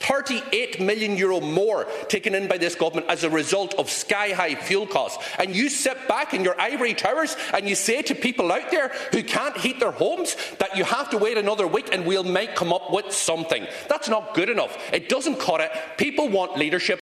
Donegal TD Pearse Doherty says next week is too late for most people: